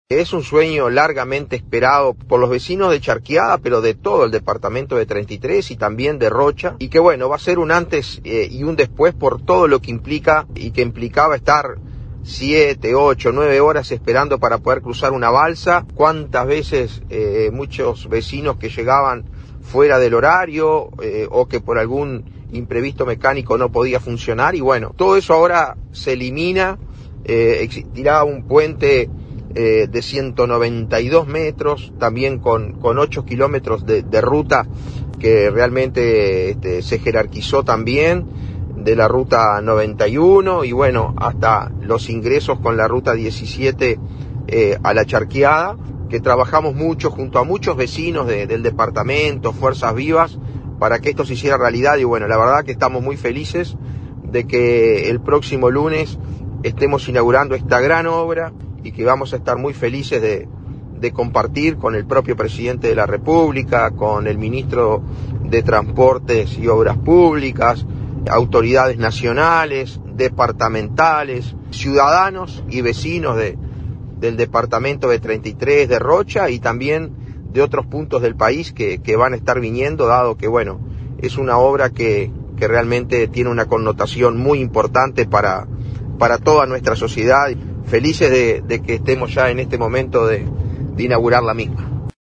La inauguración contó con la presencia del presidente, Luis Lacalle Pou.